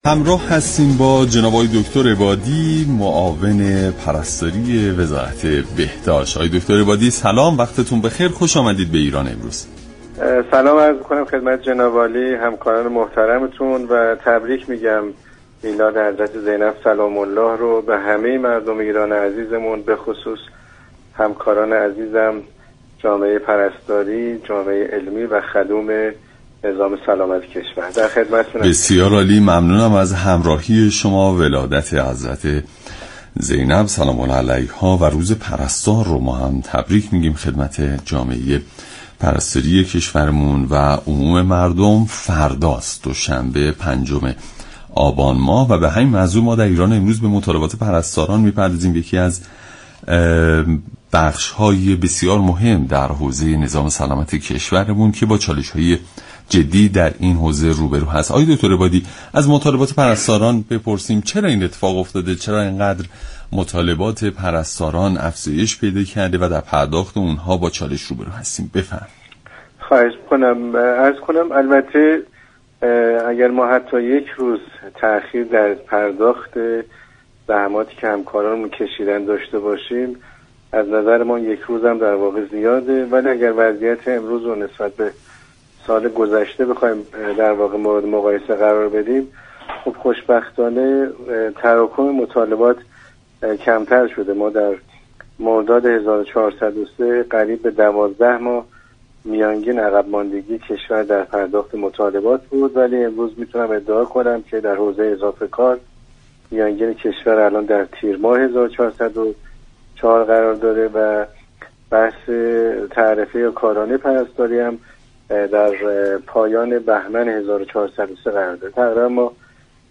معاون پرستاری وزارت بهداشت در برنامه ایران‌امروز گفت: دولت در پرداخت تعرفه پرستاری 6 ماه و در بخش اضافهكاری 3 ماه عقب‌ماندگی دارد.